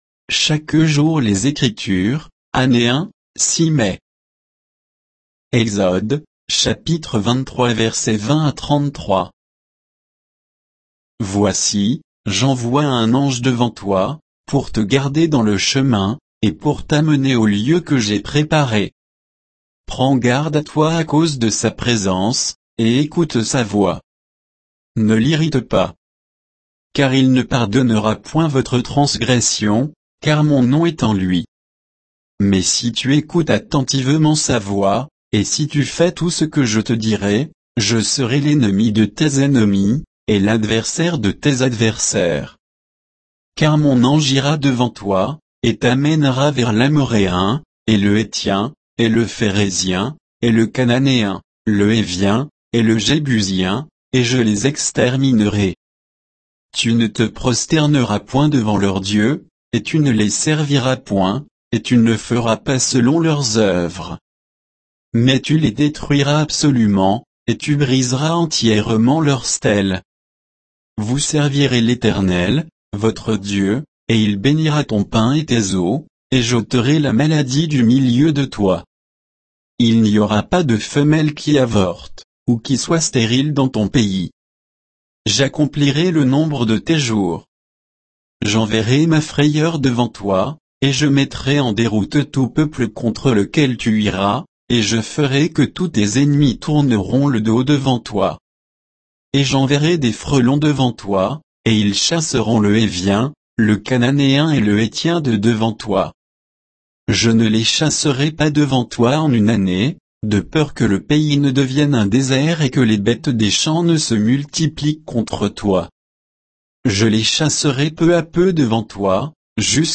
Méditation quoditienne de Chaque jour les Écritures sur Exode 23